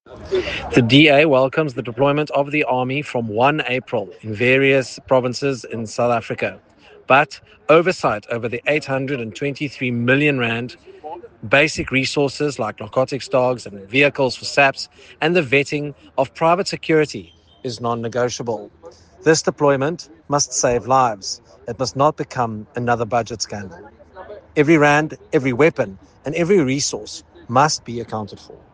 Soundbite by Nicholas Gotsell MP.
Nicholas-Gotsell-.mp3